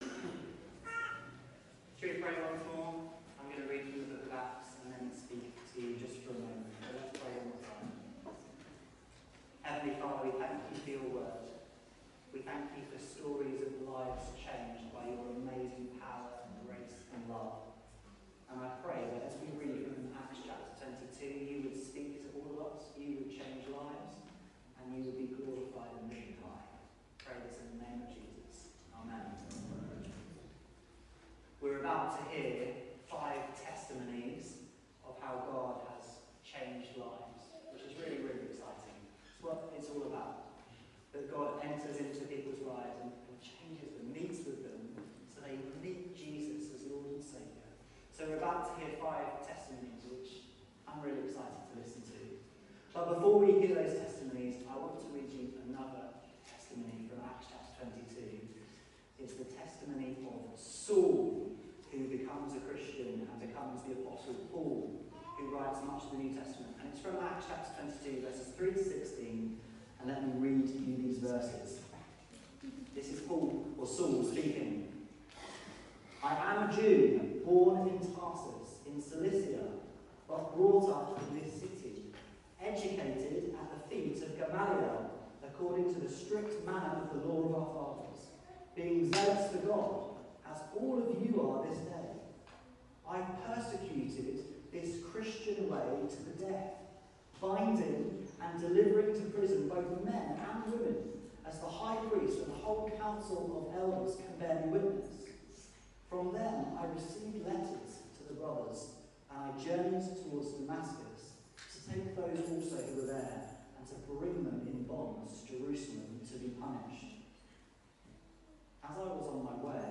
Call Upon The Name of Jesus | Baptism Service | Acts 22:3-16 |
This sermon proclaims the good news of Christianity.